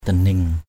/d̪a-niŋ/